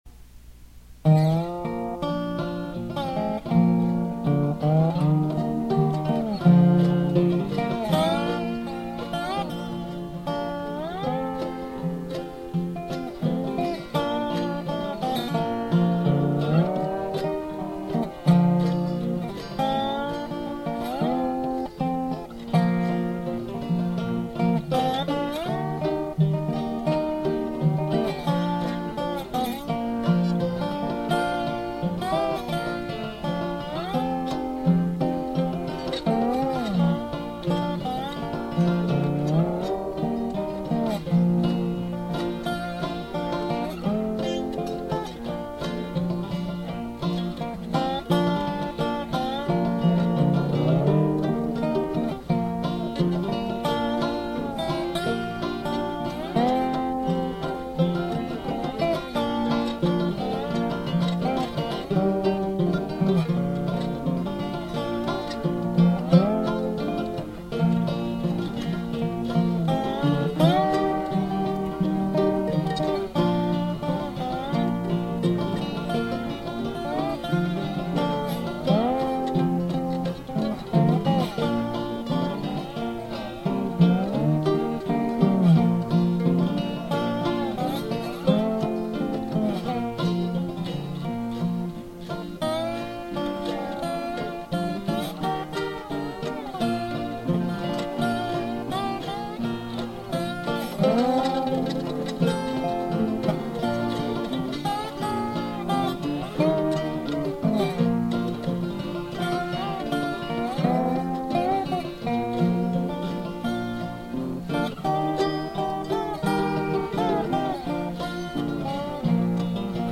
Instrumental with Dobro, Banjo, Guitar, Mandolin and Fiddle
Folk